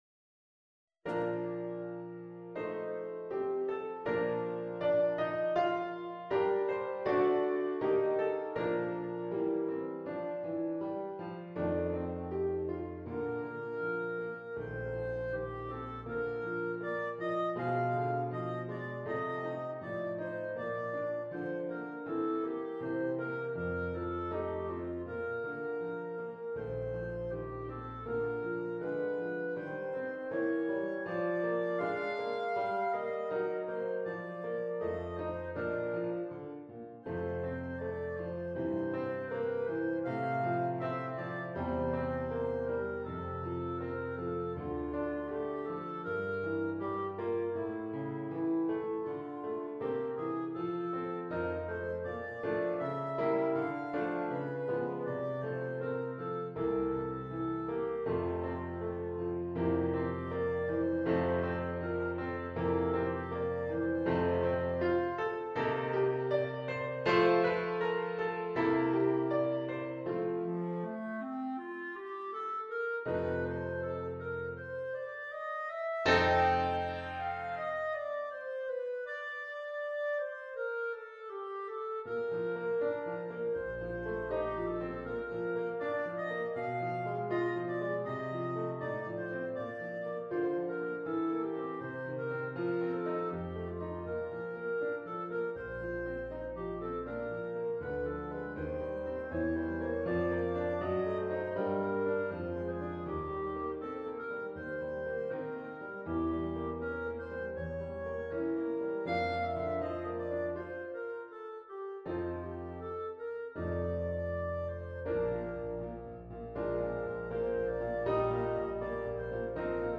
Stransky - Andante, Op. 34 - Violin | Musix4me
This is the original work for Violin.